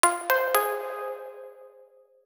alert5.wav